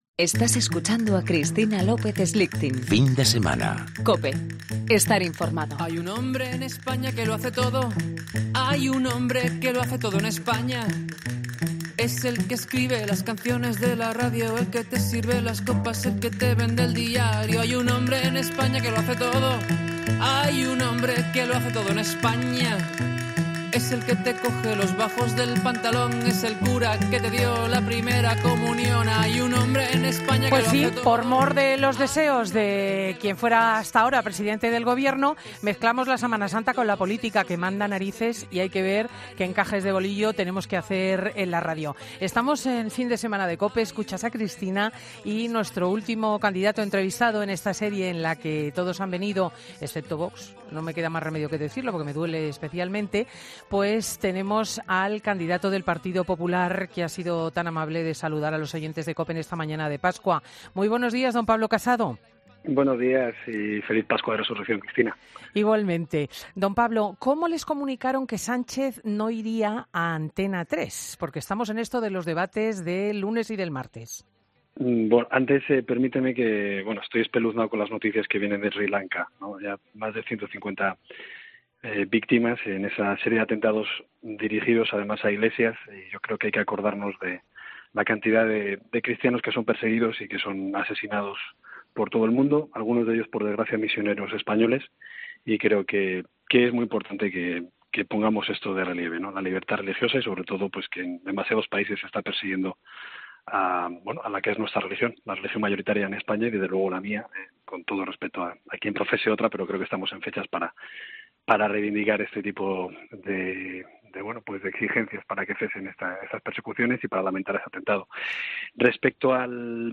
Pablo Casado , candidato del Partido Popular a la presidencia del Gobierno en las elecciones del próximo domingo 28 de abril , ha hecho especial hincapié, en la entrevista que ha concedido a 'Fin de Semana', en apelar al voto de los indecisos.